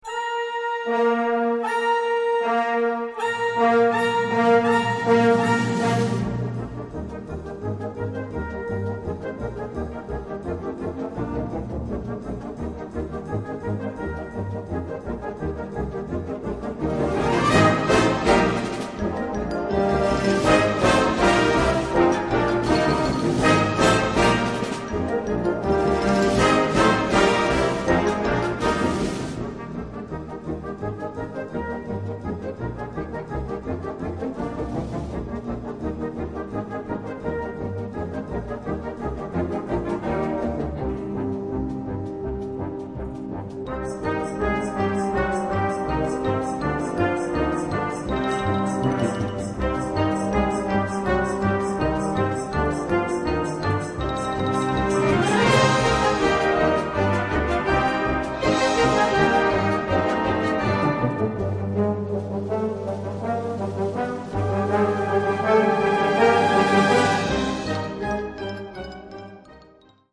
2:16 Minuten Besetzung: Blasorchester Zu hören auf